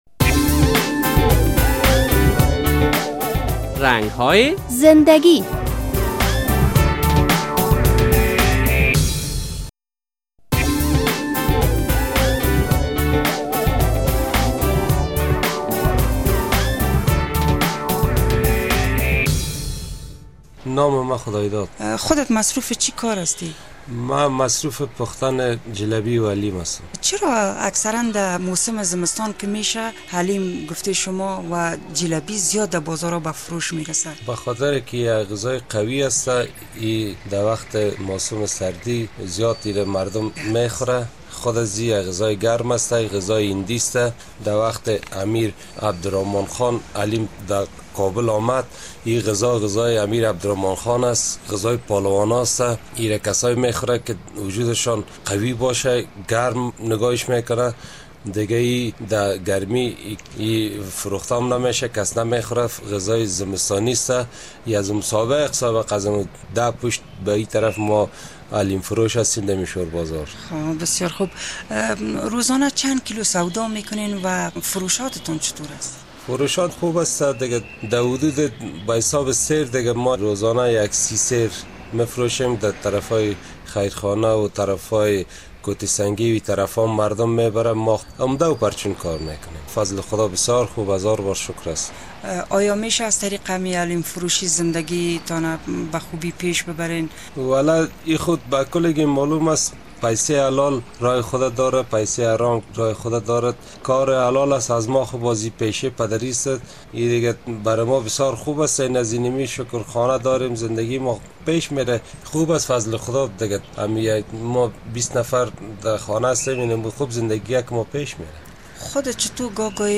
در این برنامهء رنگ های زنده گی خبرنگار رادیو آزادی با یک تن از جلبی پزان شهر کابل صحبت کرده است.